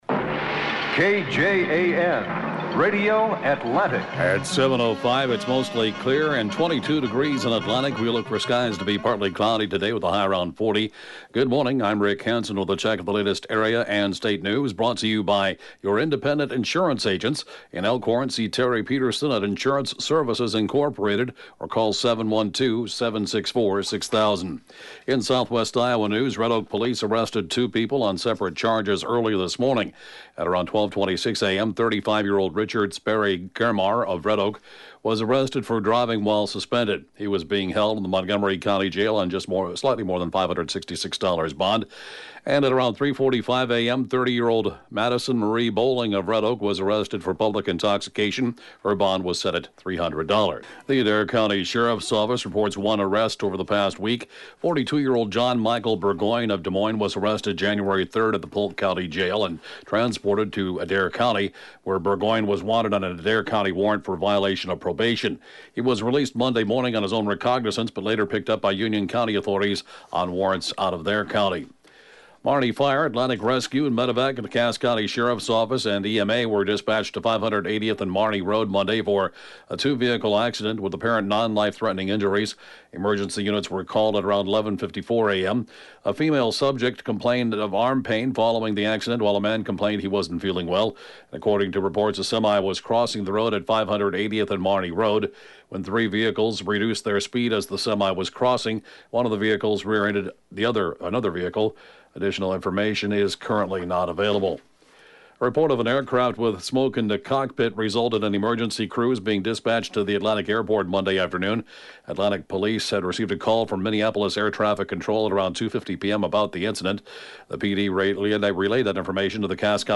(Podcast) KJAN Morning News & Funeral report, Jan. 7, 2020